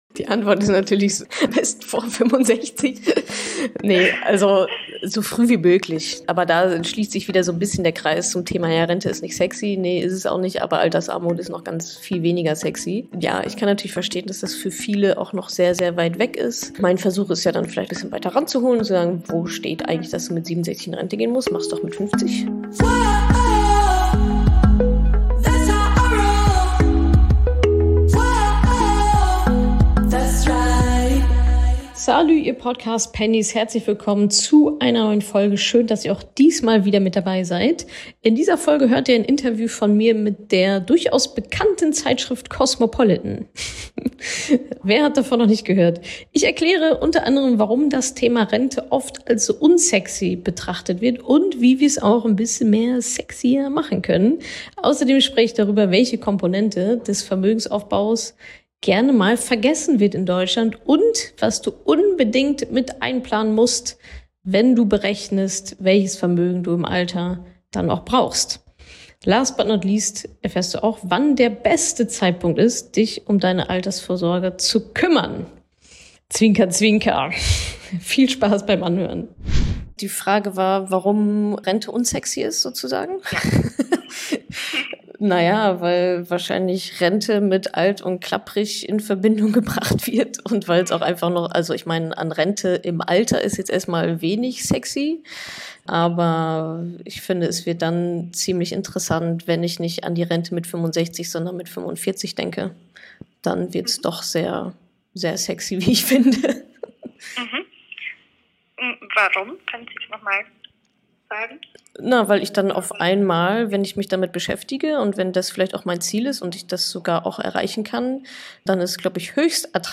Shownotes In dieser Folge hörst du ein Interview von mir mit der Cosmopolitan.